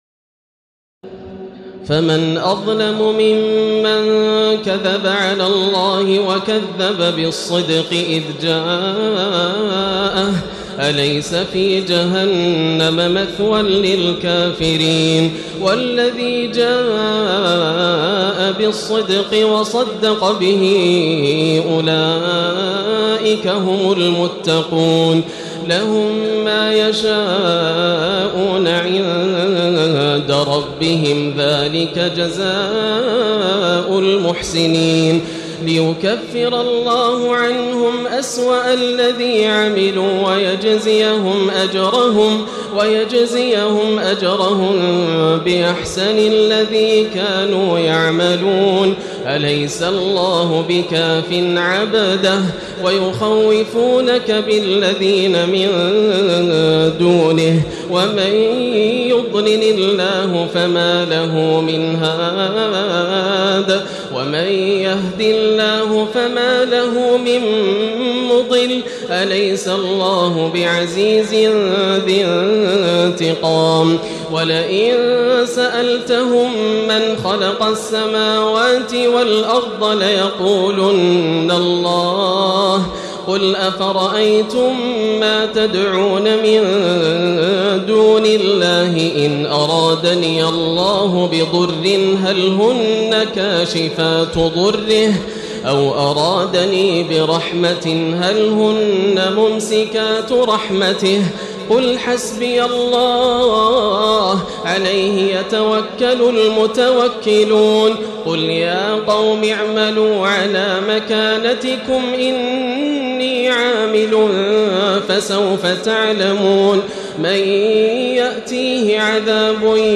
تراويح ليلة 23 رمضان 1436هـ من سور الزمر (32-75) و غافر (1-46) Taraweeh 23 st night Ramadan 1436H from Surah Az-Zumar and Ghaafir > تراويح الحرم المكي عام 1436 🕋 > التراويح - تلاوات الحرمين